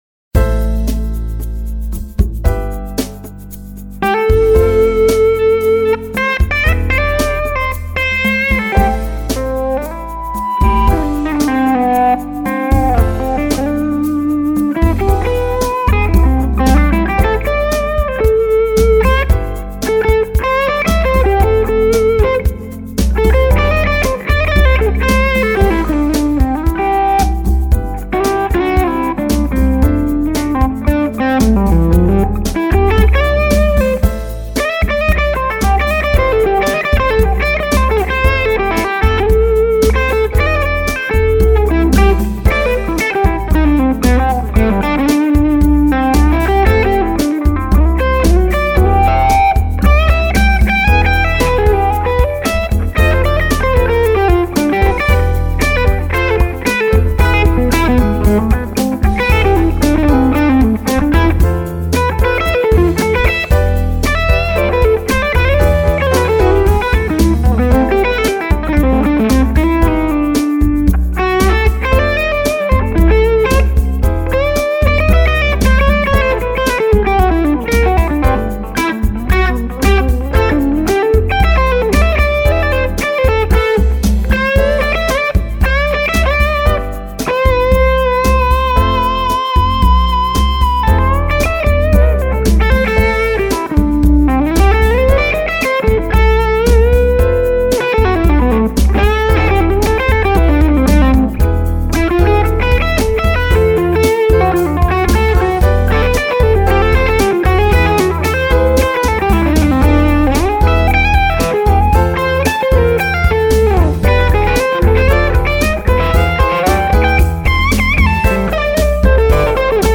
Amp is the Bludo Ojai w/Loopalator.
Recorded with no effects, just 200pf cables to and from loop.
Glaswerks 2X12 w/Celestion G1265 Multimiced with SM57 and GT Hardtop.
This morning I swapped in my Phillips 7581 output tubes.
Mix has a touch less verb and a touch more delay.
Seems the 7581 has more harmonics on top and are clearer in the bass.
Yeah!! 7581's you can hear the low mid howl.. caught that right off!!
Aussen6Ojai7581.mp3